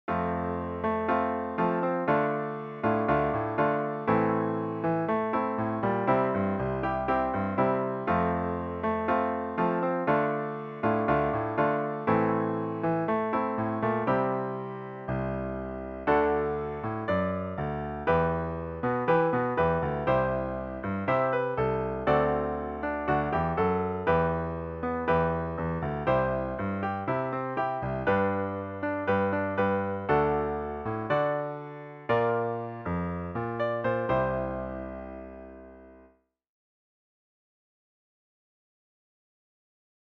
Each one is in two parts, where the first part acts as a verse progression, and the second one works as a chorus. The end of each chorus progression gives you a way back to the verse.
1. From F major to G major: F  Dm  Am  C  F  Dm  Am  C  |D  Em  C  D7  Em  C  G  D  Bb  C [